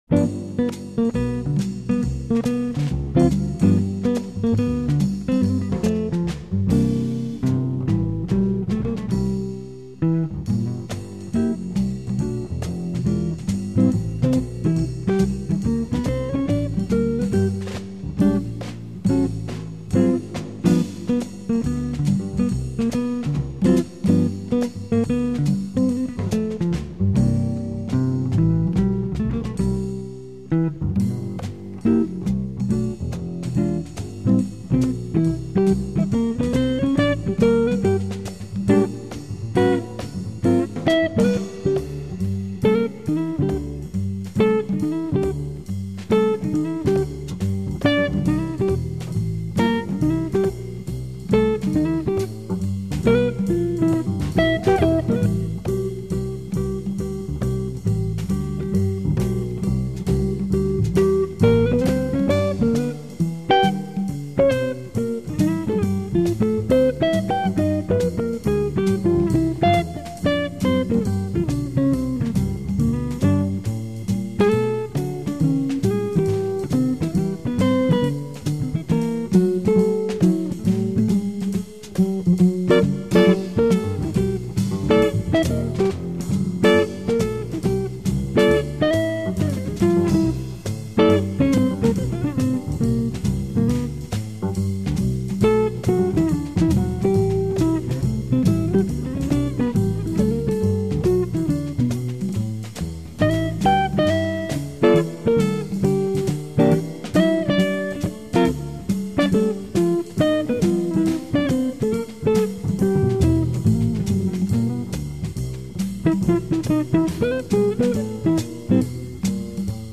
E-Gitarre zu spielen,
mit dem Daumen –
ohne Plektrum.
in session